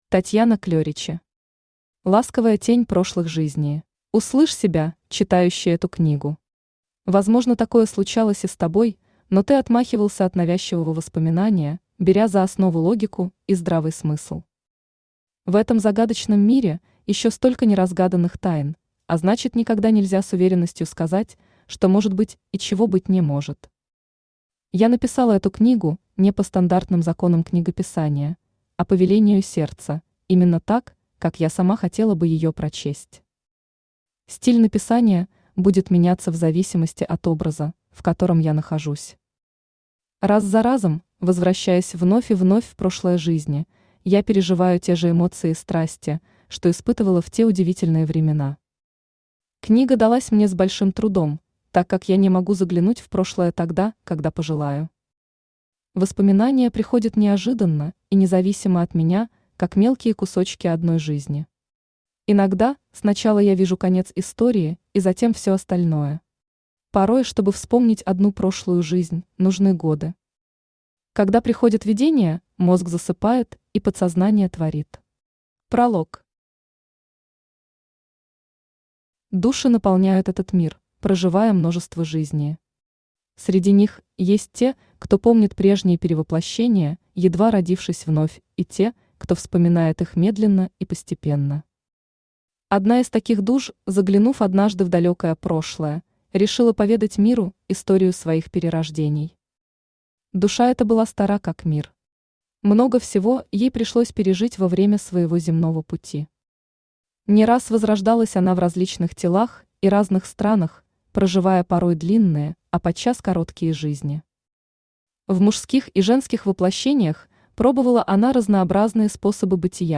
Аудиокнига Ласковая тень прошлых жизней | Библиотека аудиокниг
Aудиокнига Ласковая тень прошлых жизней Автор Татьяна Клеричи Читает аудиокнигу Авточтец ЛитРес.